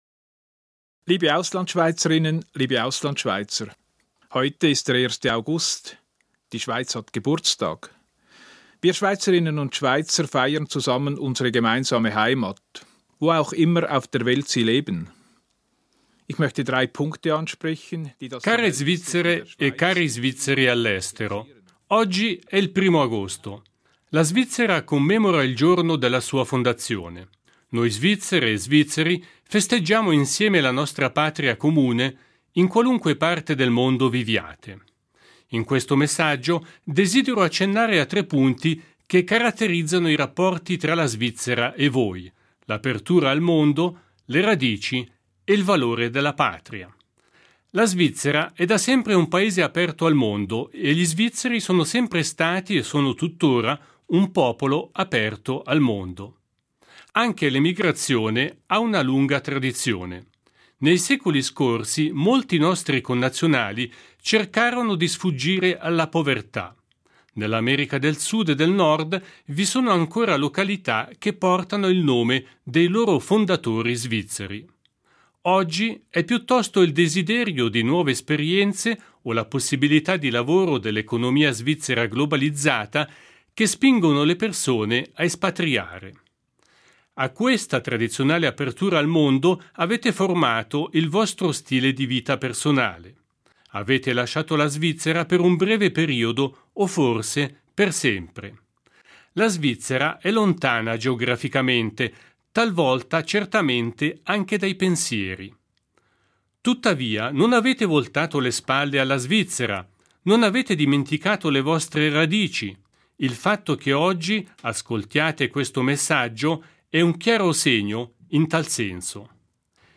Messaggio del presidente della Confederazione Ueli Maurer agli Svizzeri all’estero in occasione della Festa nazionale.